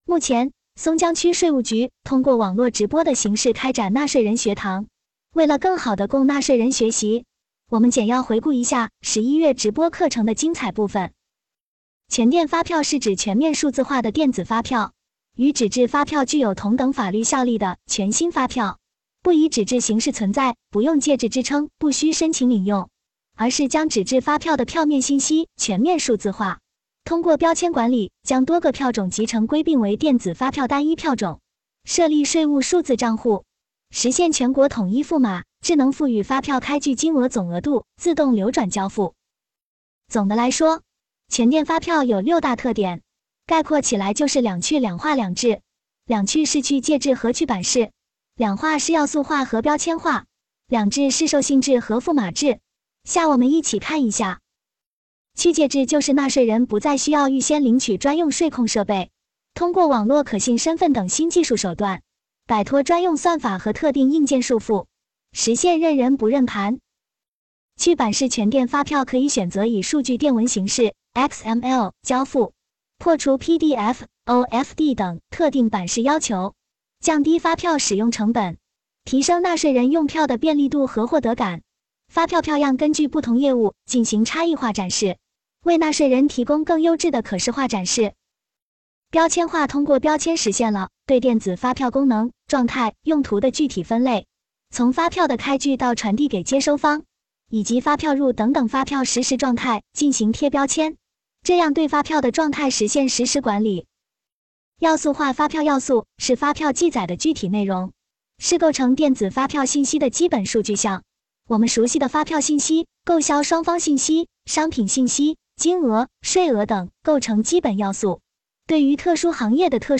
直播课程一